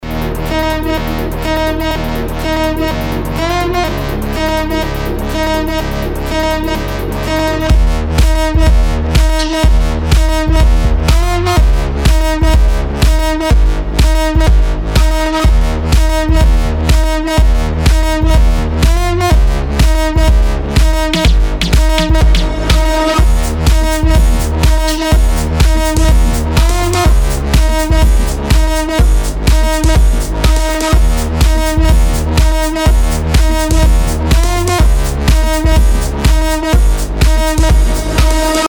• Качество: 320, Stereo
future house
Разгоняющаяся хаус музыка